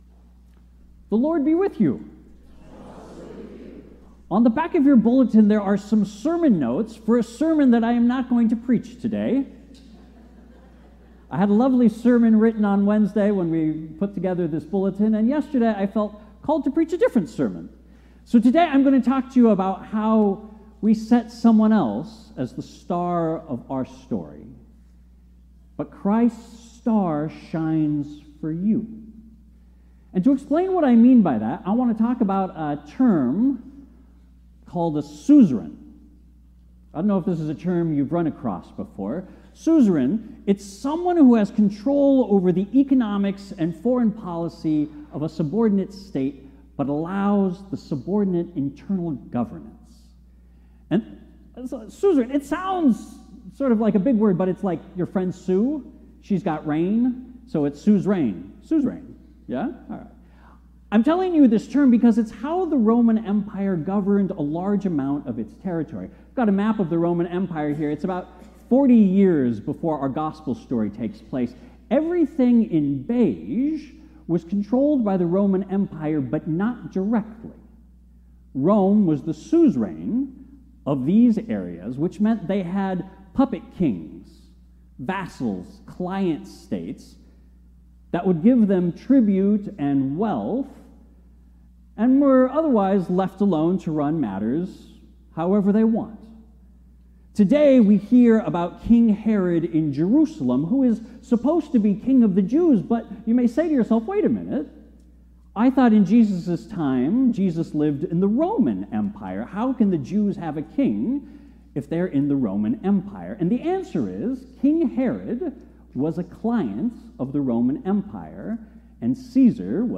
Sermons preached at Faith Lutheran Church in Chico, CA